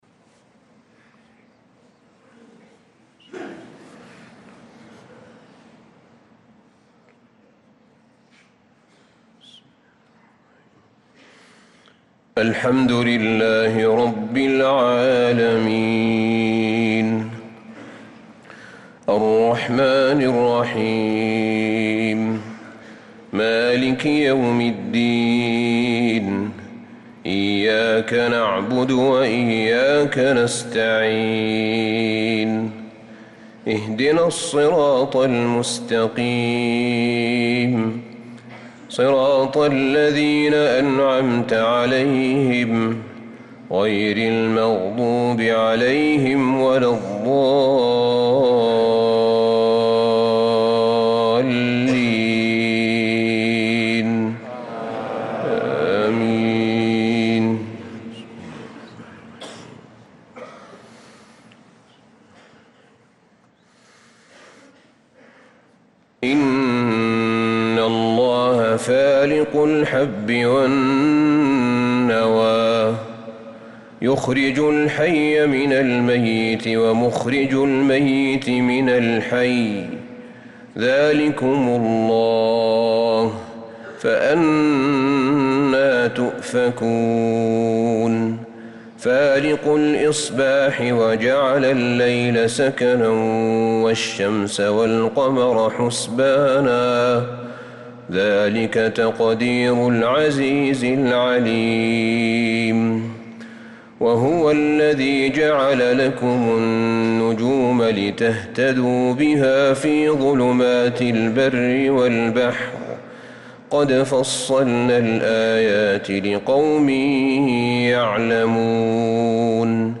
صلاة الفجر للقارئ أحمد بن طالب حميد 22 ذو الحجة 1445 هـ
تِلَاوَات الْحَرَمَيْن .